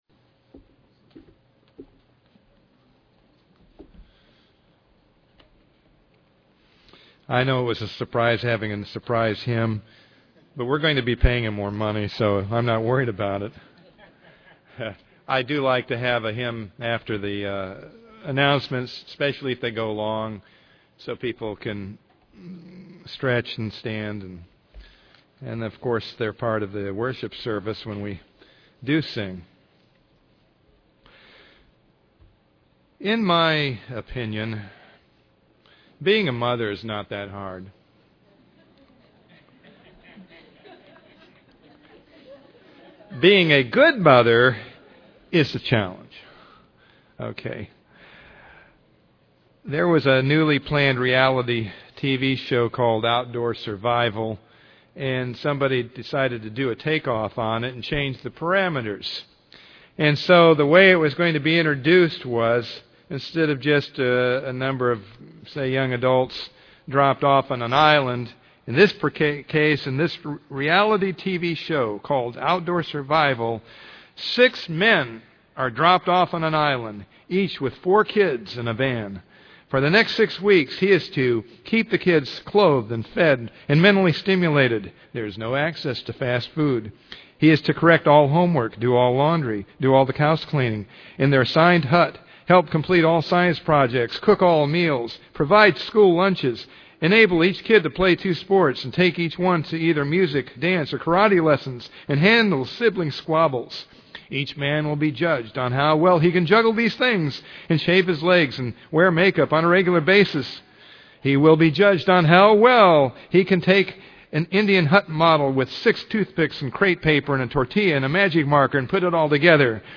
Given in Ft. Myers, FL Tampa, FL
UCG Sermon Studying the bible?